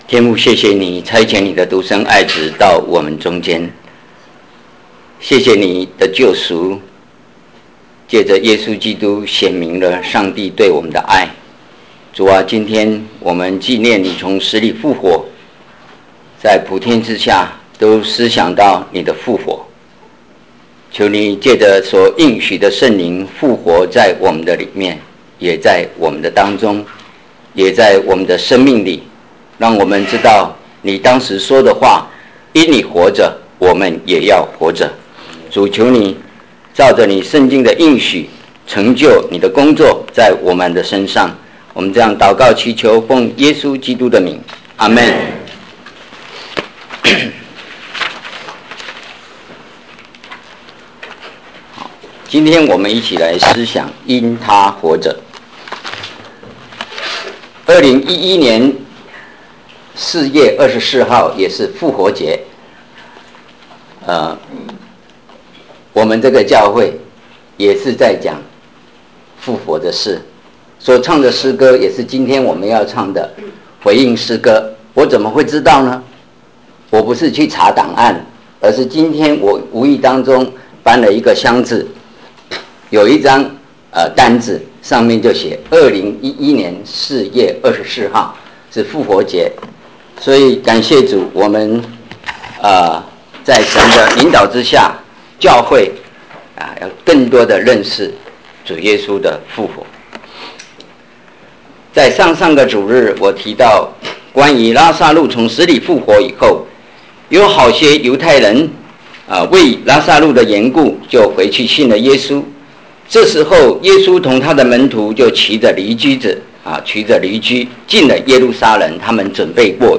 因祂活着 - 三城华人教会